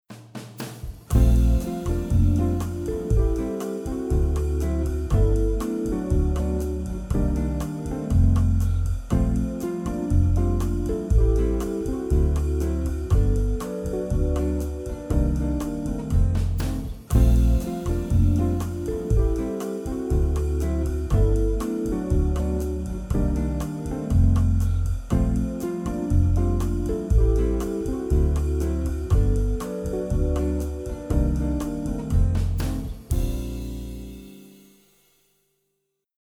Akkordprogression med modulation og gehørsimprovisation:
Lyt efter bassen, der ofte spiller grundtonen.
Modulation: En lille terts op eller en lille terts ned
C instrument (demo)